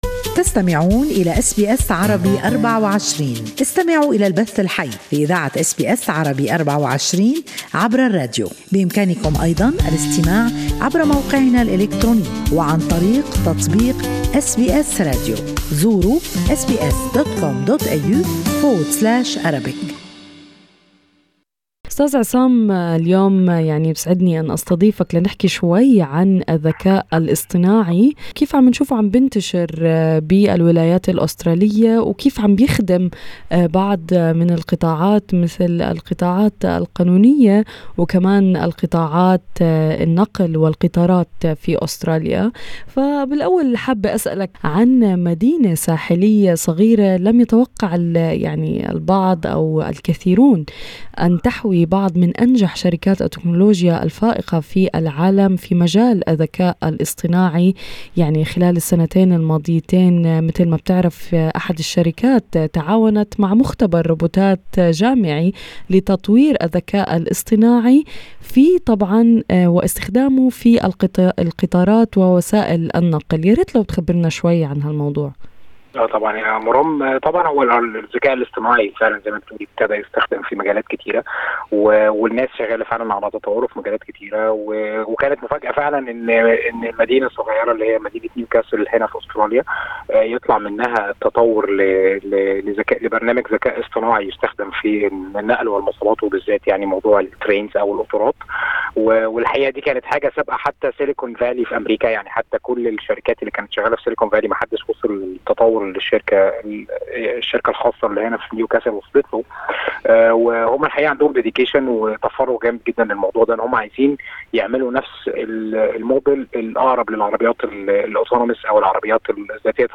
يخبرنا المزيد عن هذه التطورات في المقابلة أعلاه